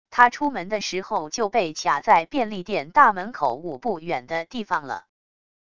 他出门的时候就被卡在便利店大门口五步远的地方了wav音频生成系统WAV Audio Player